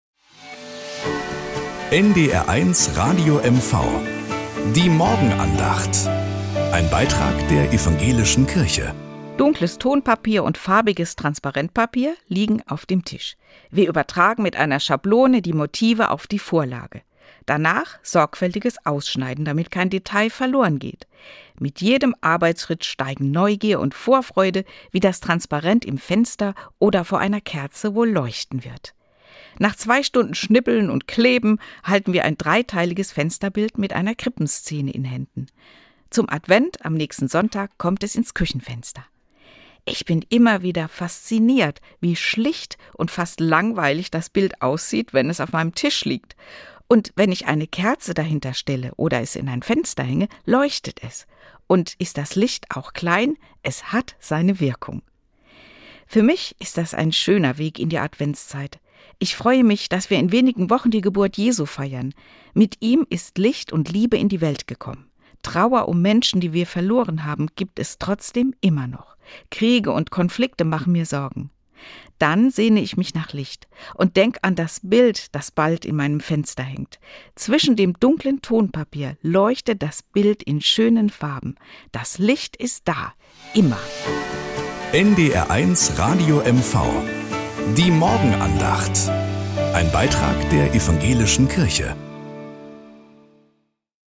1 Morgenandacht bei NDR 1 Radio MV 1:34